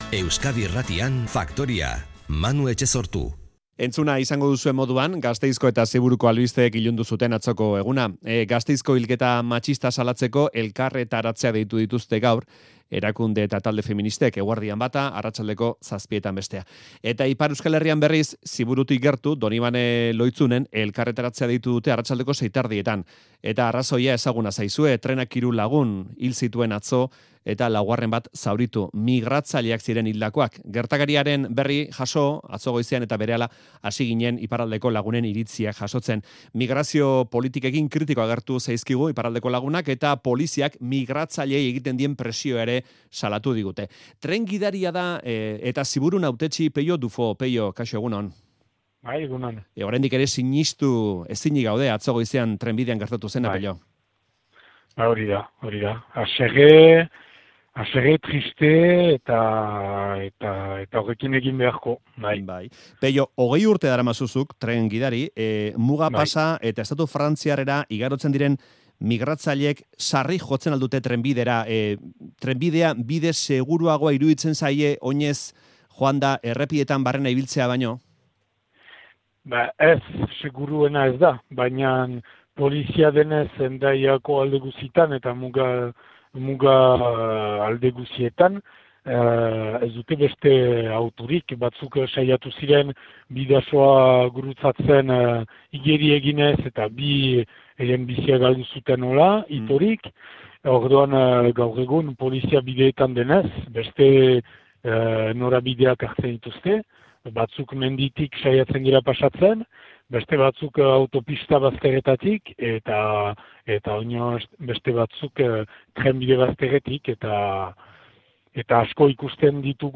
Audioa: Peio Dufau, Ziburuko hautetsi eta tren gidariarekin aztertu dugu trenak harrapatuta hil diren hiru migratzaileen gertaera larria.